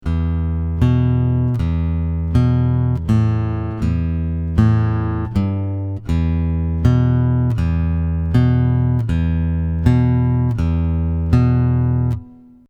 Easy Acoustic Blues Pattern – Bass
easy-blues-bassline.mp3